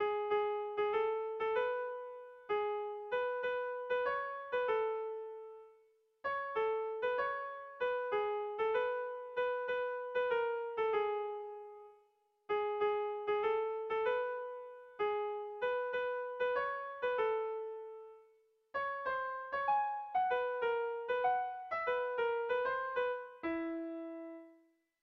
Sentimenduzkoa
Zortziko txikia (hg) / Lau puntuko txikia (ip)
ABAD